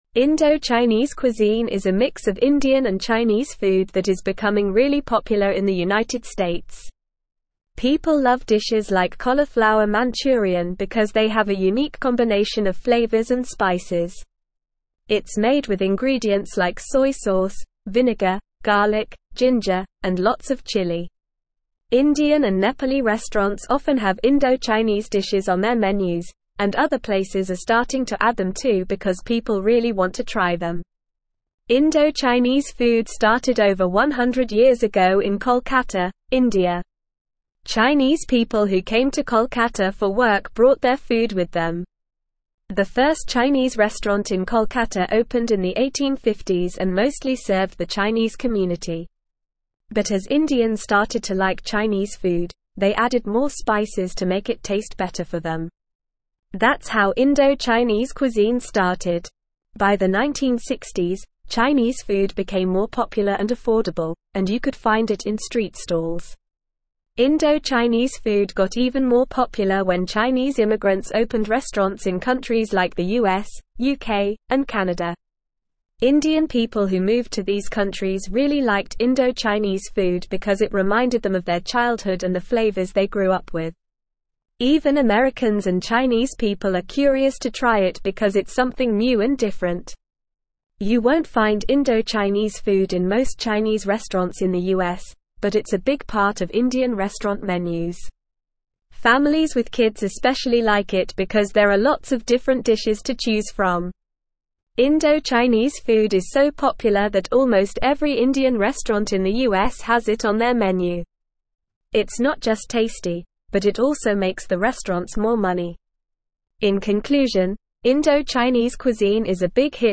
Normal
English-Newsroom-Upper-Intermediate-NORMAL-Reading-Indo-Chinese-cuisine-gaining-popularity-in-US-restaurants.mp3